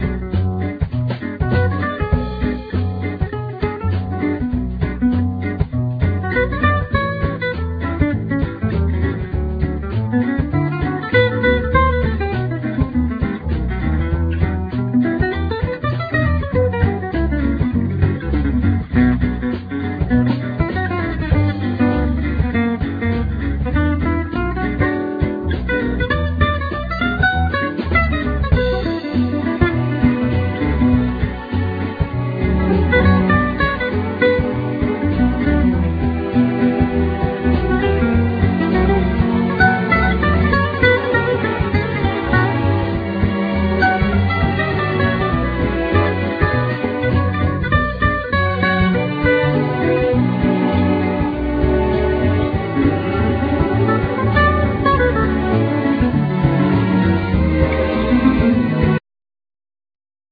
Violin
Viola
Cello
Flute
Clarinet
Harp
Drums
Accordeon
Guitars,Percussions